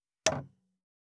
258,食器にスプーンを置く,ガラスがこすれあう擦れ合う音,カトラリーの音,食器の音,会食の音,食事の音,カチャン,コトン,効果音,環境音,BGM,カタン,
コップ